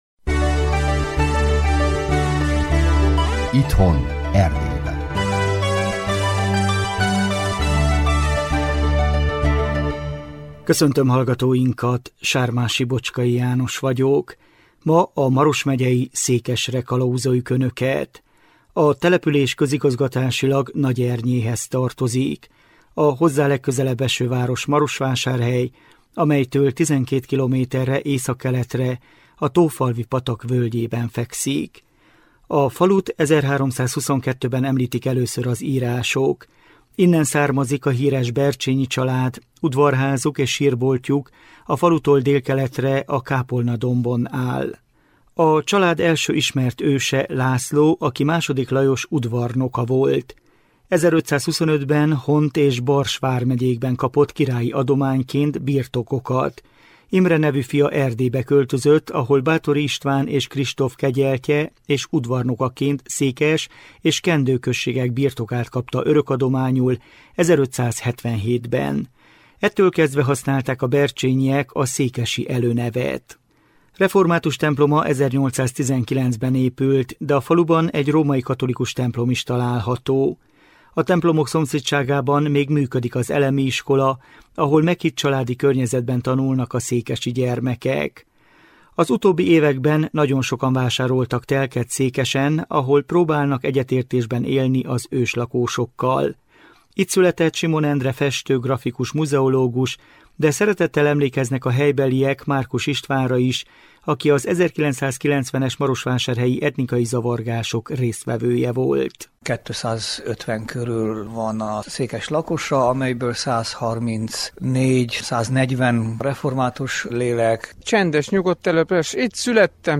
Műsorunk a 2024 november 24-i műsor ismétlése!